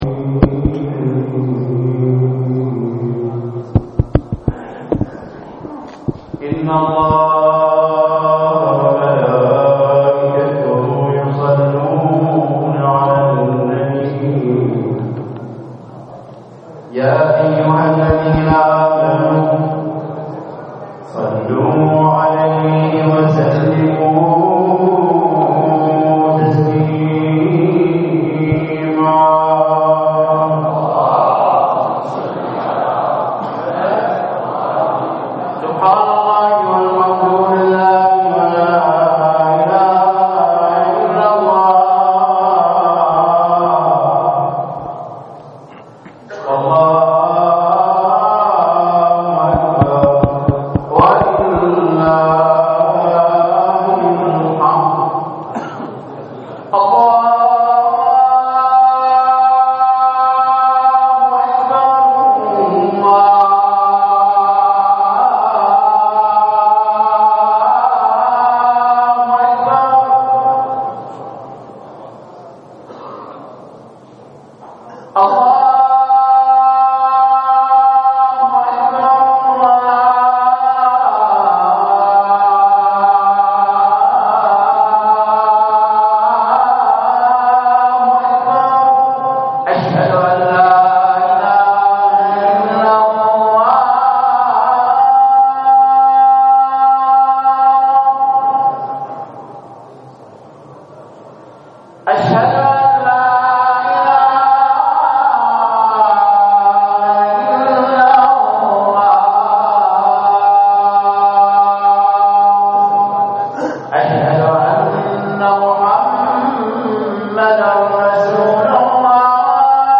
للاستماع الى خطبة الجمعة الرجاء اضغط هنا